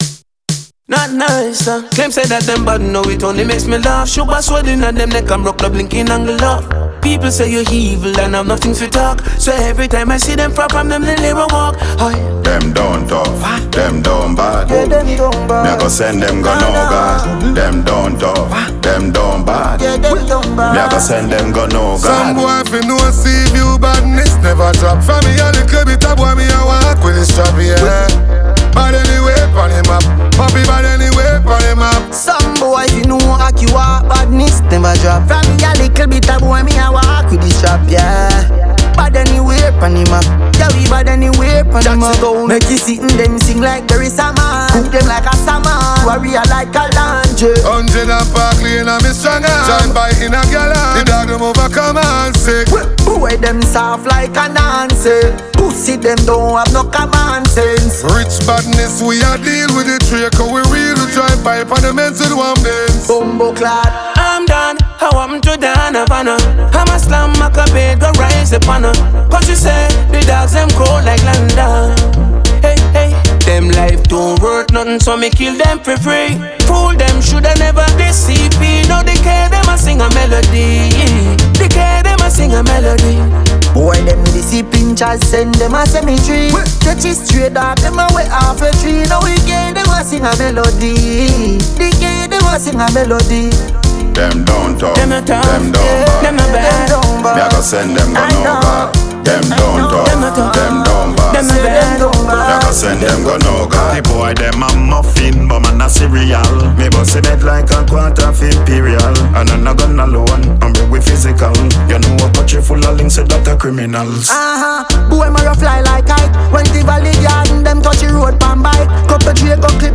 Check out this new banger from Dancehall artist